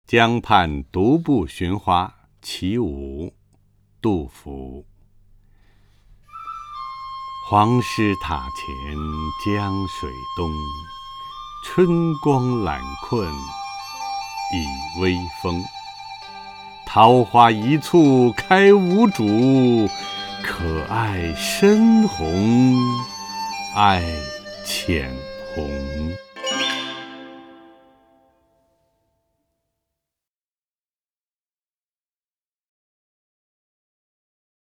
首页 视听 名家朗诵欣赏 陈铎
陈铎朗诵：《江畔独步寻花七绝句·其五》(（唐）杜甫)